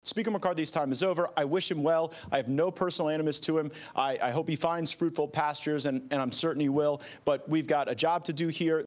Gaetz pushed back against McCarthy, saying this to reporters.